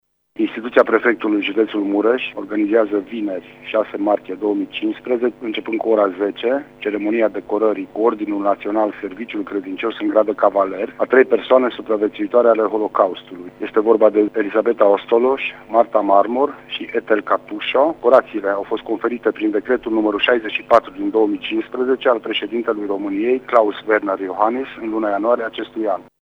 Cei trei vor primi Ordinul Naţional Serviciul Credincios în grad de Cavaler, conferit prin decret prezidenţial în luna ianuarie, a explicat prefectul judeţului Mureş, Lucian Goga: